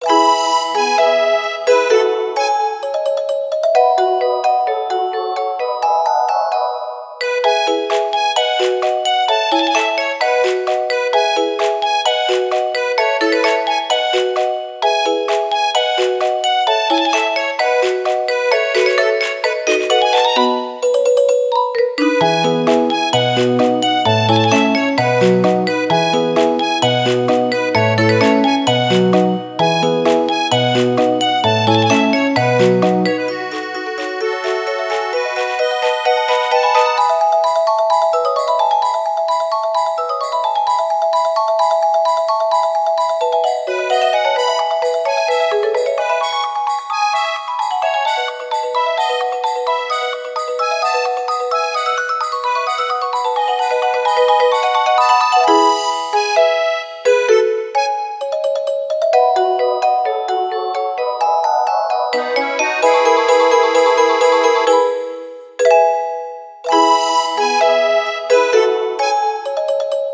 ogg(L) メルヘン かわいい ほのぼの